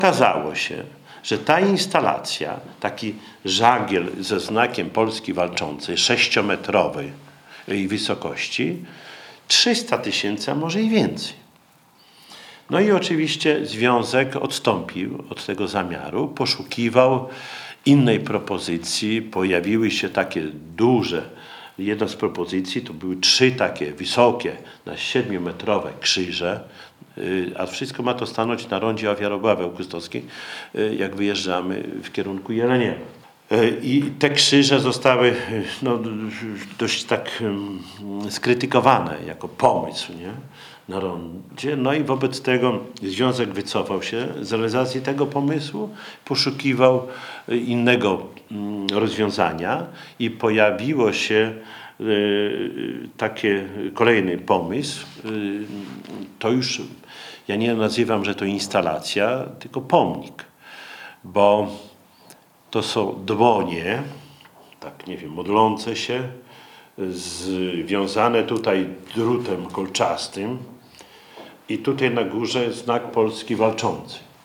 O szczegółach mówił w poniedziałek (20.11), na konferencji prasowej Czesław Renkiewicz, prezydent Suwałk.